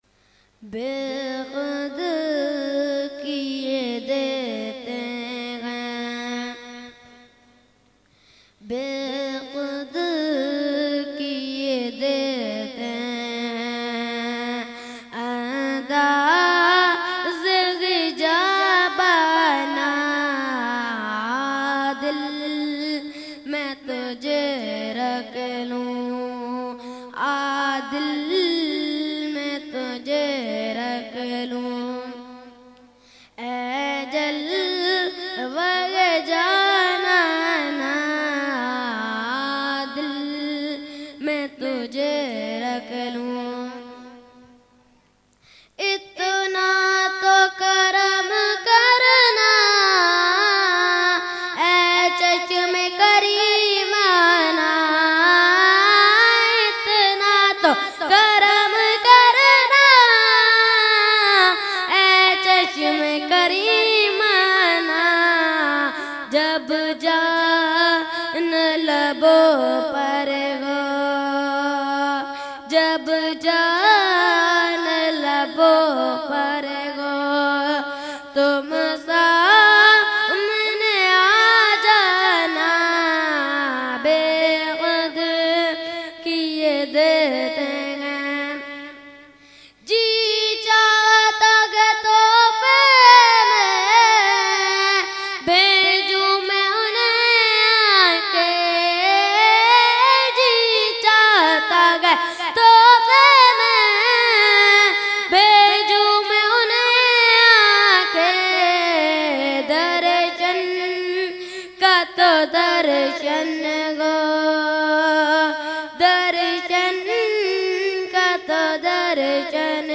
Mehfil e 11veen Shareef held 11 December 2020 at Dargah Alia Ashrafia Ashrafabad Firdous Colony Gulbahar Karachi.
Category : Naat | Language : UrduEvent : 11veen Shareef 2020